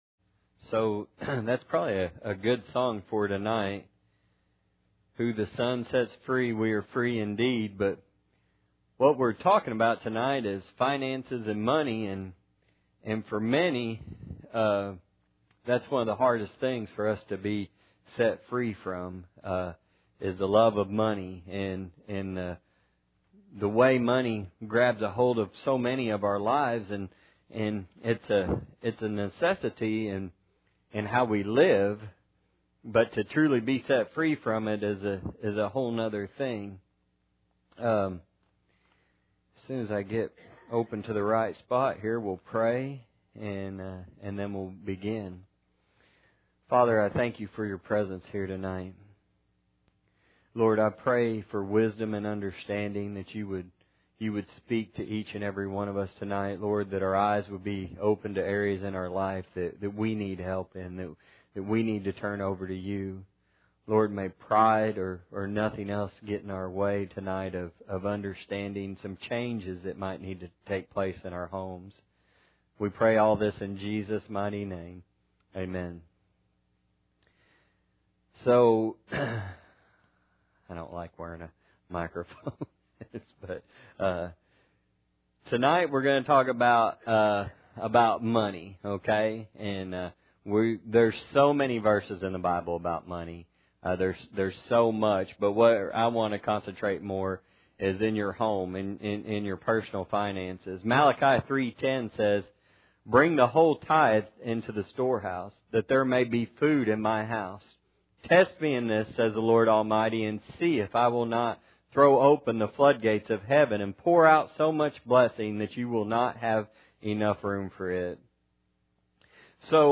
Luke 12:15 Service Type: Sunday Night Bible Text